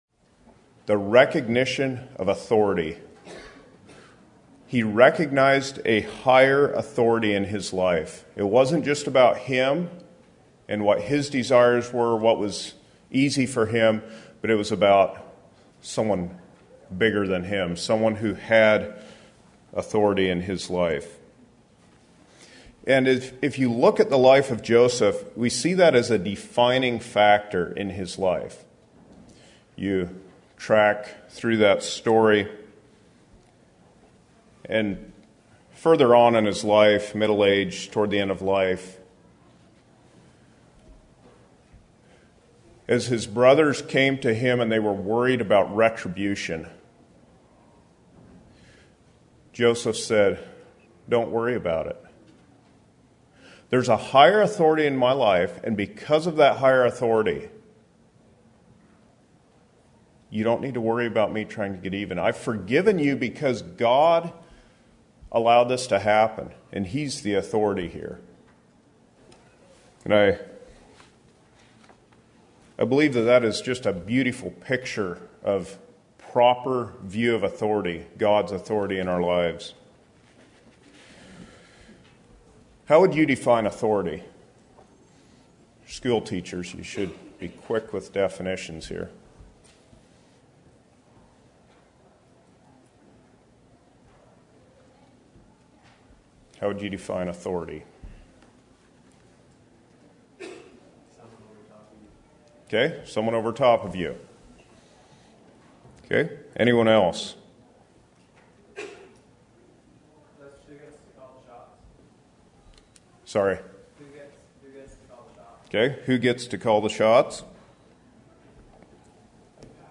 Home » Lectures » God’s Authority in My Life
Western Fellowship Teachers Institute 2023